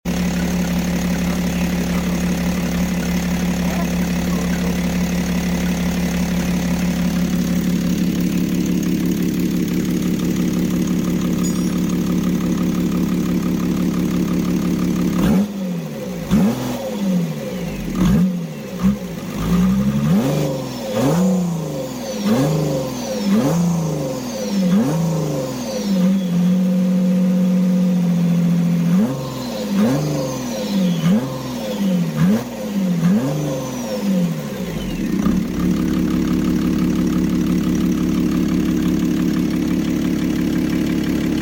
Audi 2.5TDi V6 Running on sound effects free download
Audi 2.5TDi V6 Running on Mechanicial Pump